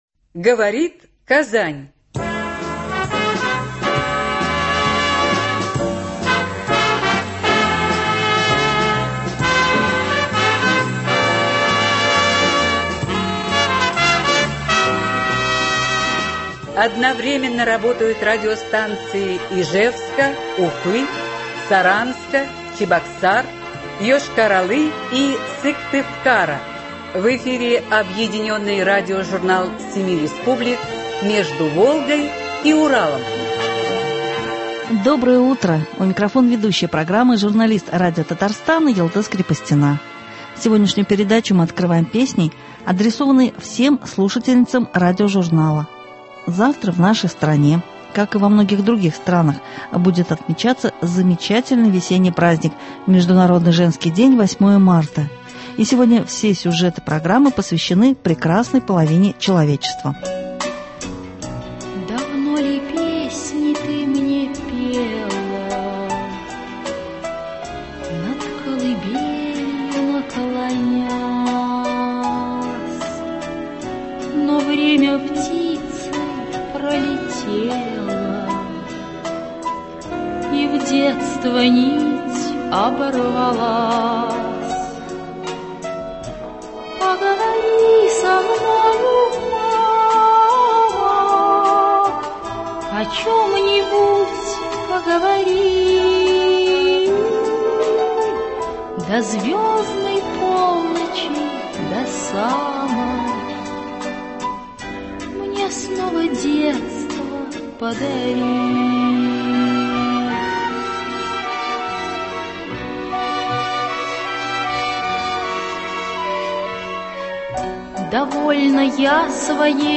Объединенный радиожурнал семи республик.
Сегодняшнюю программу мы открываем песней, адресованной всем слушательницам радиожурнала.